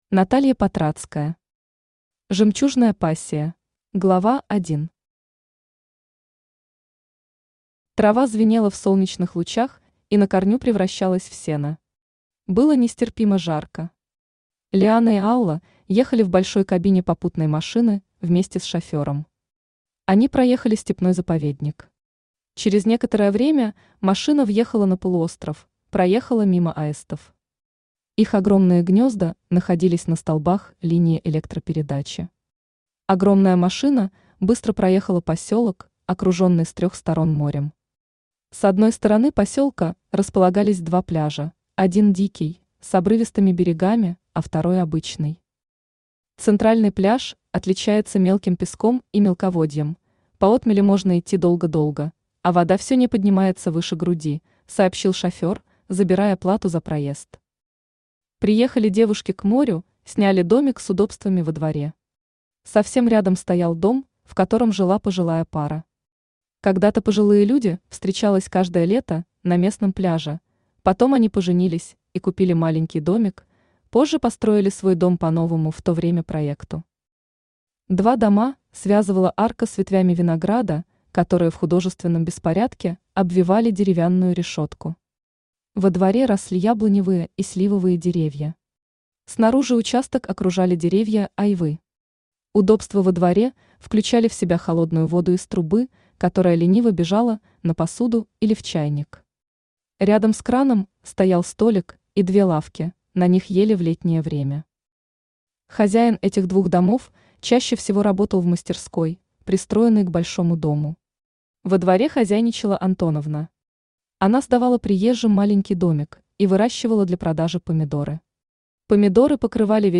Аудиокнига Жемчужная пассия | Библиотека аудиокниг
Aудиокнига Жемчужная пассия Автор Наталья Владимировна Патрацкая Читает аудиокнигу Авточтец ЛитРес.